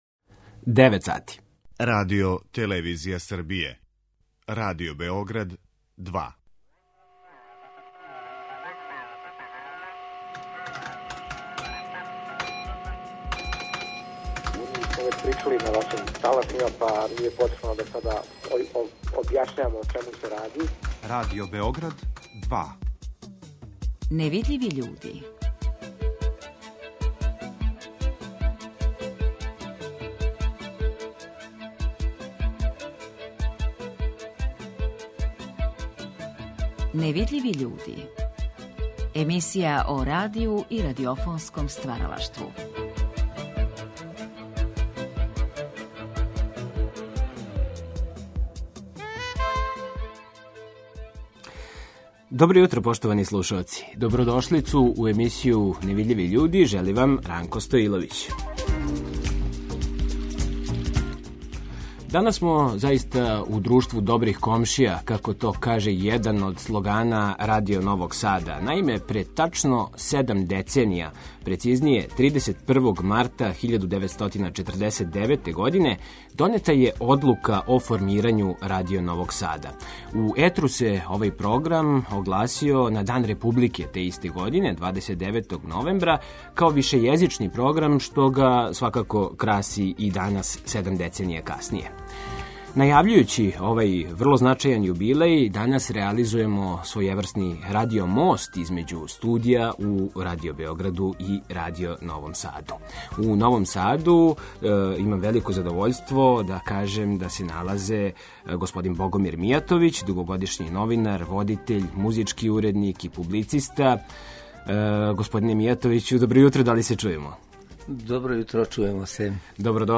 Радио-мост између студија у Радио Београду и Радио Новом Саду.